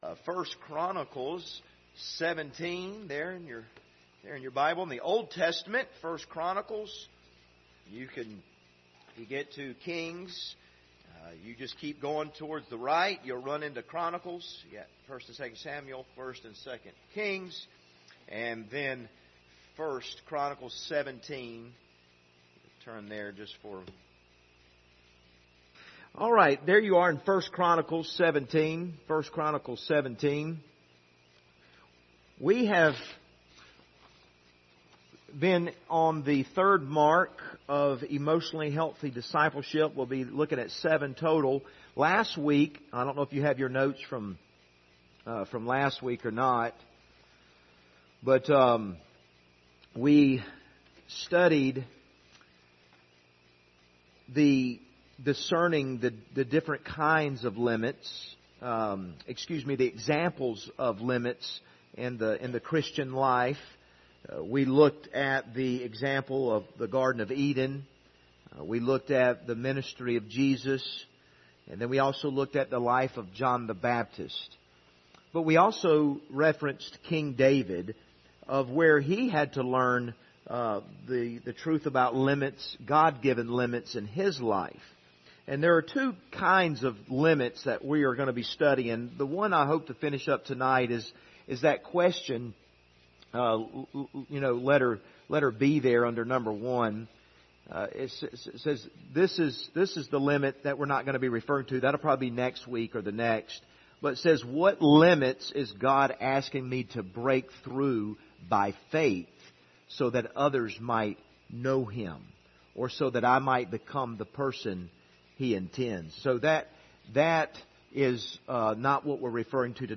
Passage: 1 Chronicles 17 Service Type: Wednesday Evening